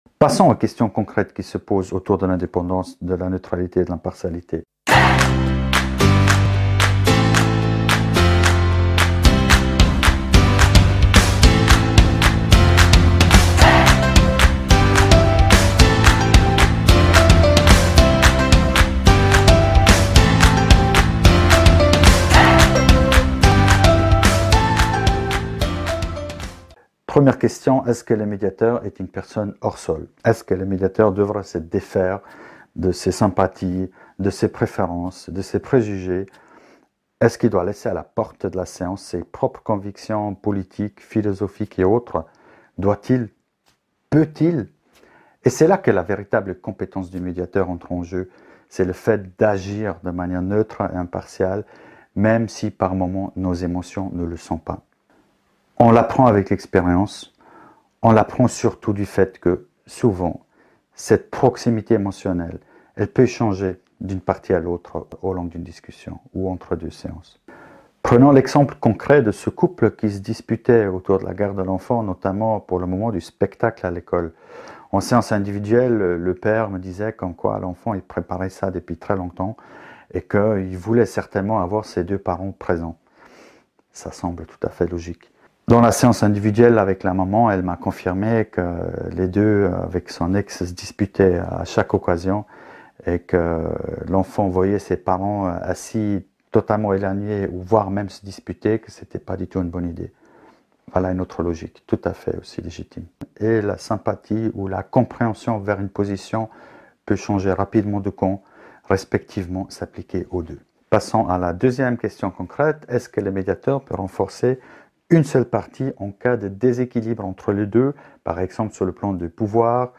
Cours en ligne – La médiation: outils, concepts, approches